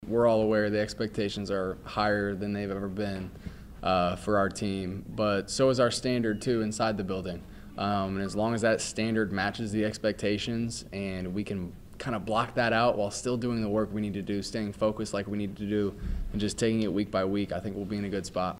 Mizzou player cuts from SEC Media Days.